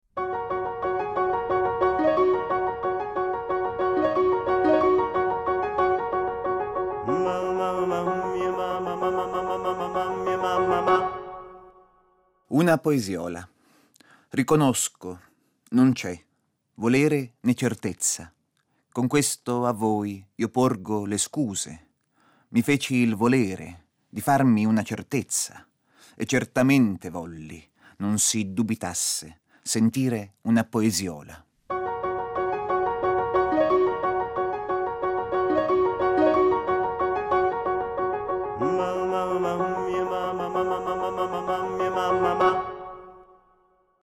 Poesia